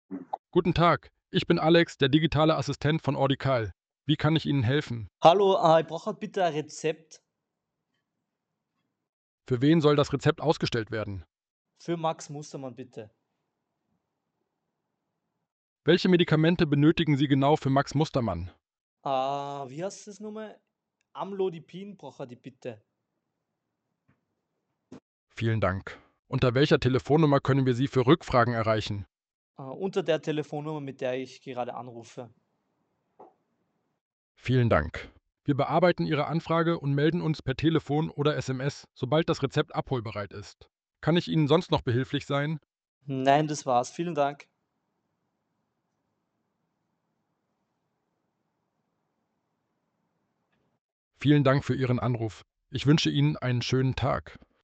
So unterstützt Ordicall bei Rezeptanfragen – mit passenden Nachfragen für einen klaren Ablauf.